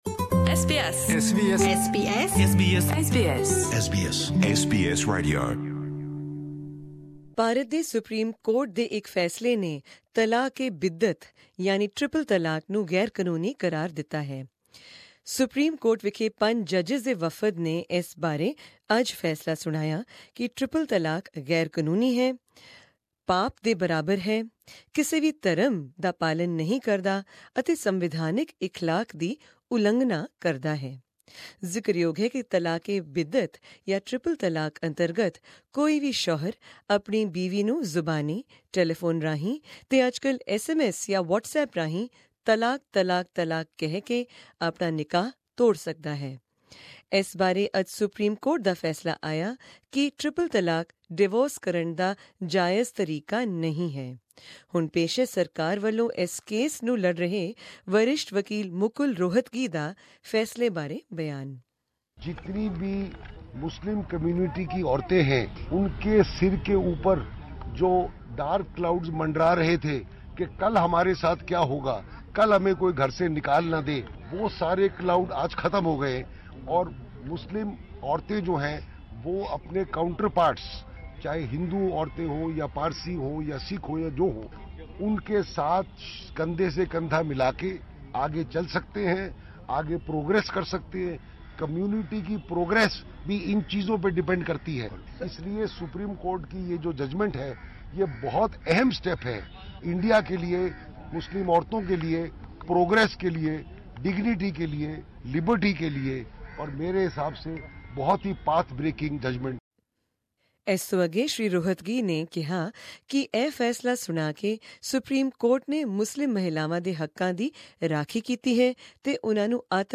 Hear the above report, in which you'll also hear from the Senior Counsel representing the Indian government at this case, Mukul Rohtagi.